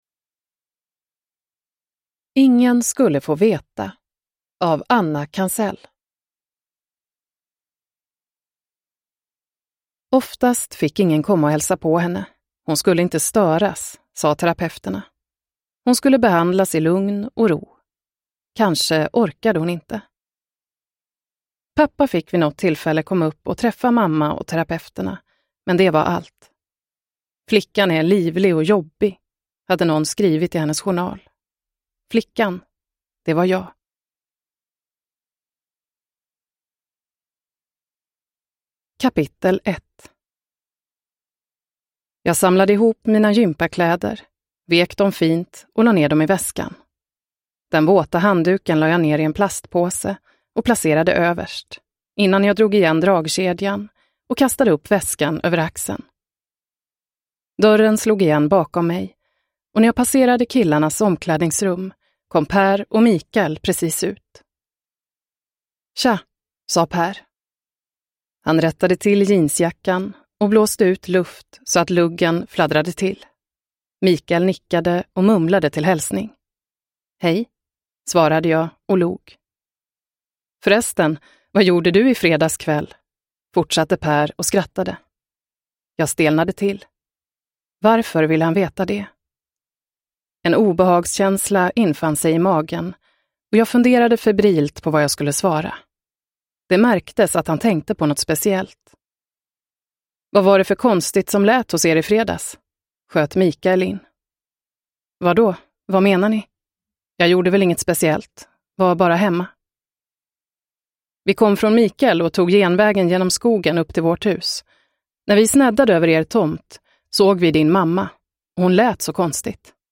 Ingen skulle få veta – Ljudbok – Laddas ner